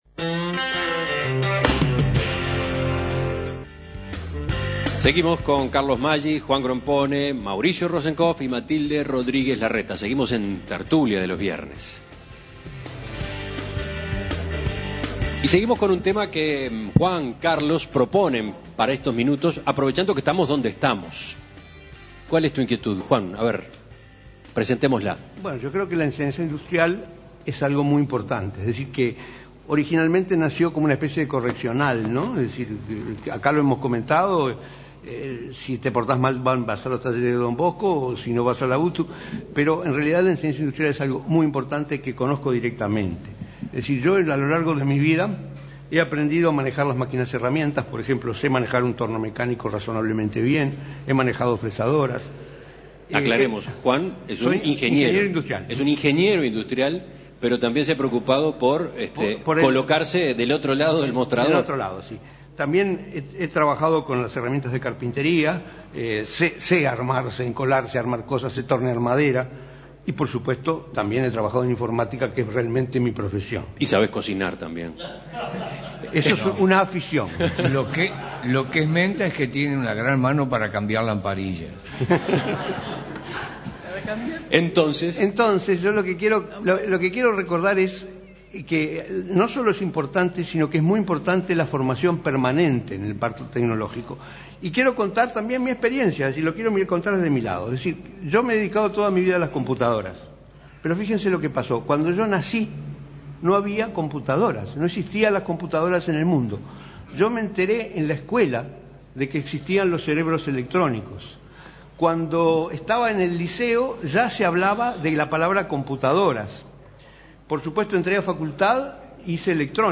A propósito de que nos encontramos instalados en Talleres Don Bosco, conversamos sobre la enseñanza industrial, sobre cómo avanza la tecnología y cómo debemos adaptarnos rápidamente a ella